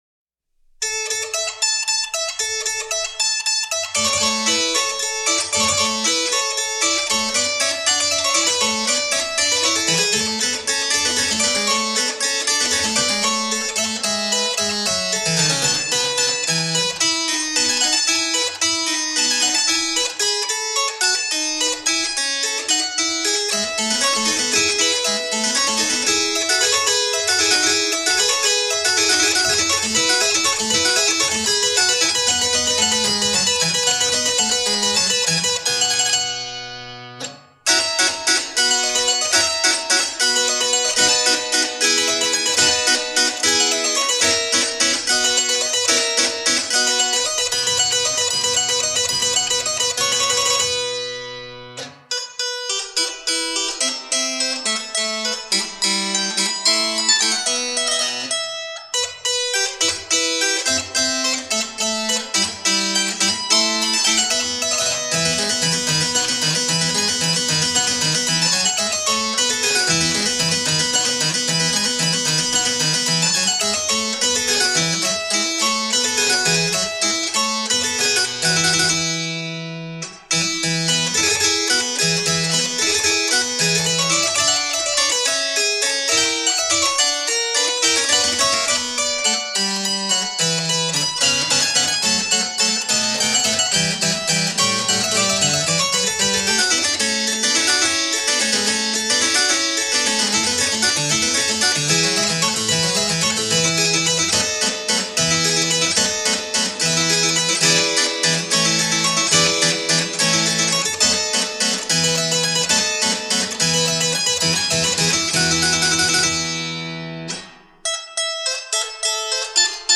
I - Three Centuries of Harpsichord — A Plucky Alternative